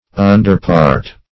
Underpart \Un"der*part`\, n.